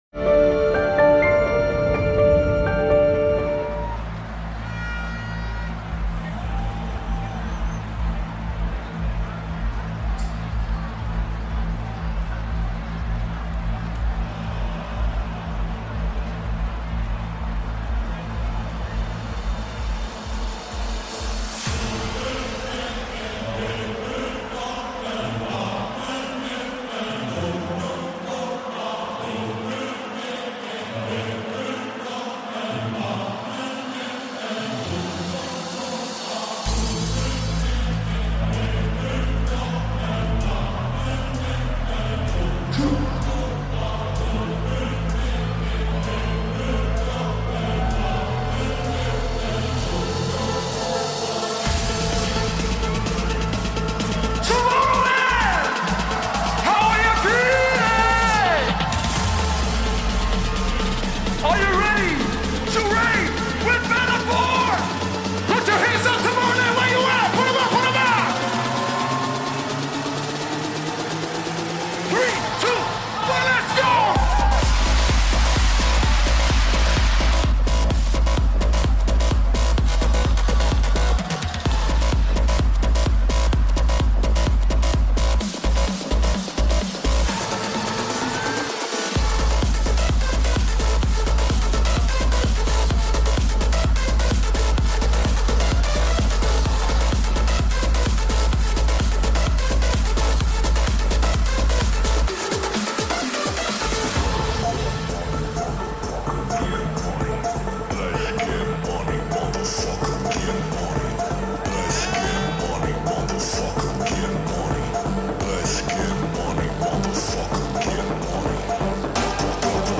Also find other EDM Livesets, DJ